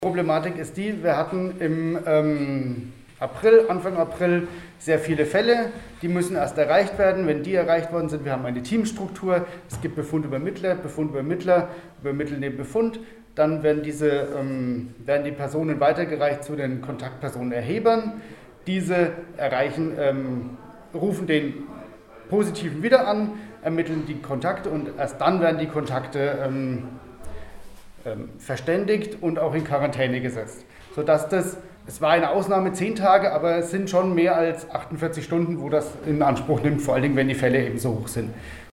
Besuch im Gesundheitsamt Schweinfurt- Alle Interviews und Videos zum Nachhören - PRIMATON